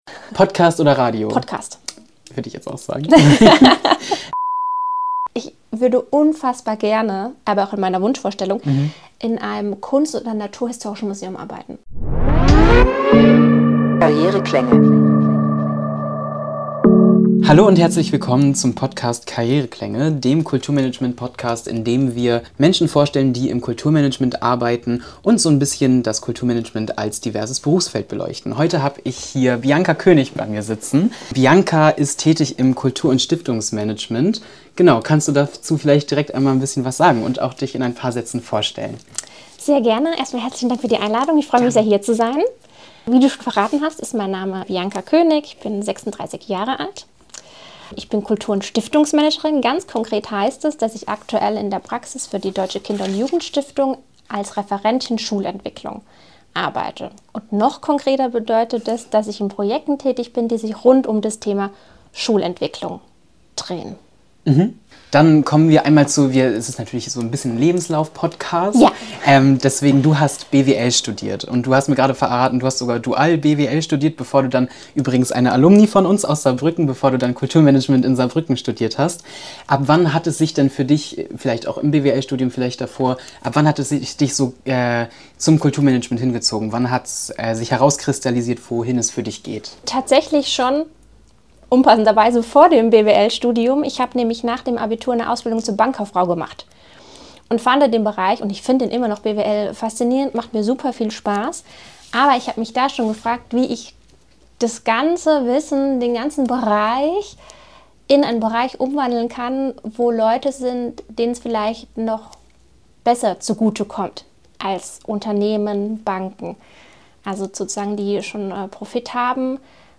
Karriereklänge – Talk